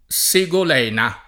Sigolena [ S i g ol $ na ] o Segolena [ S e g ol $ na ] pers. f. stor.